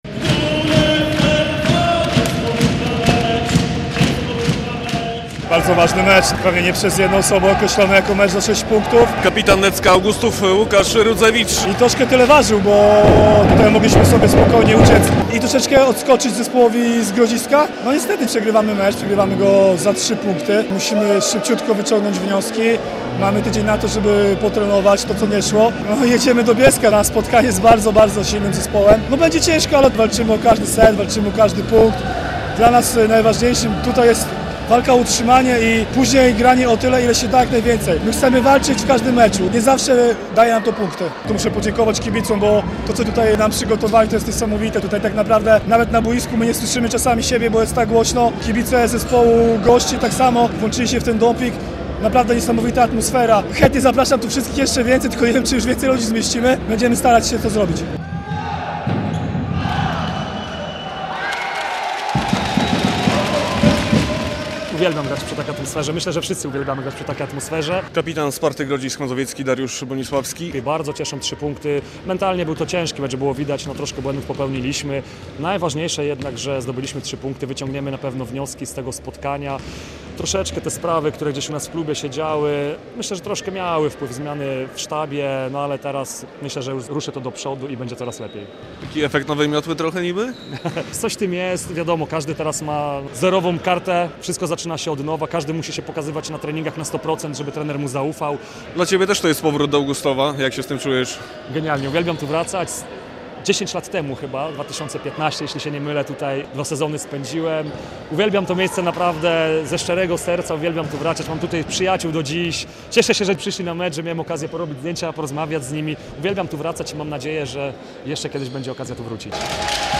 Necko Augustów przegrało 1:3 ze Spartą Grodzisk Mazowiecki w 9. kolejce I ligi siatkarzy - relacja